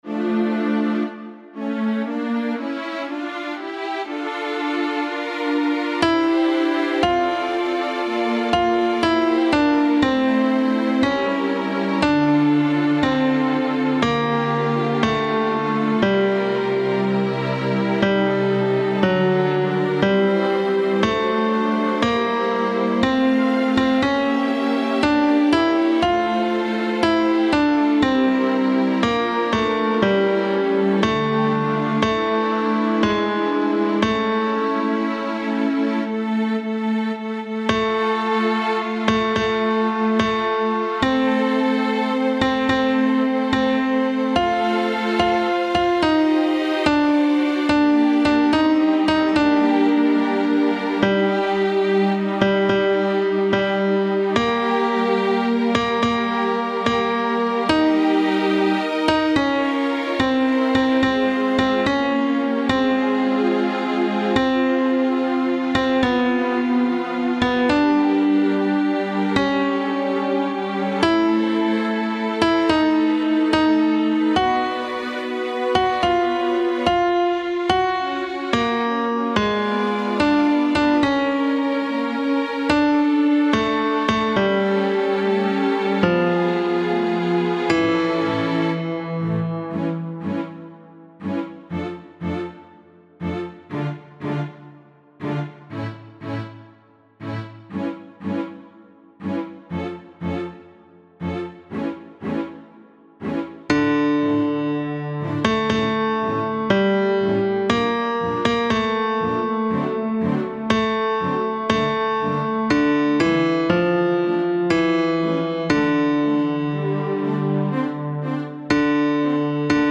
Tenor I
Mp3 Música